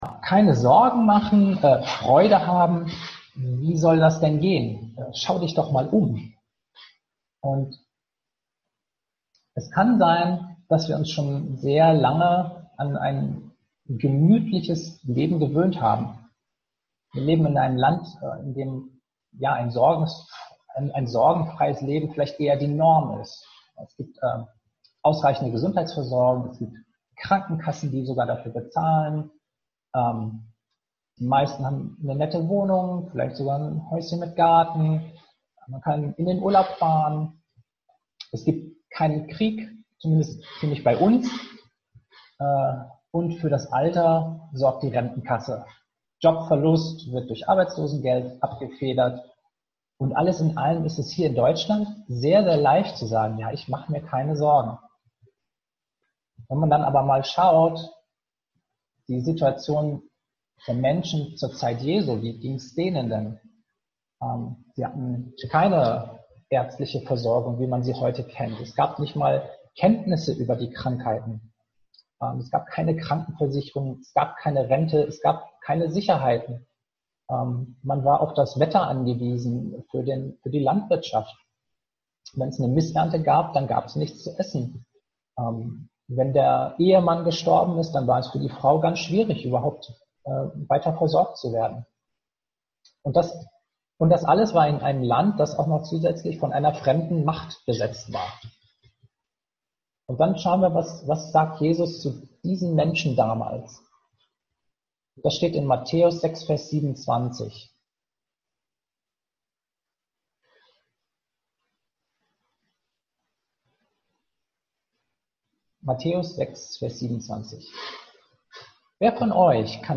Sonntagspredigt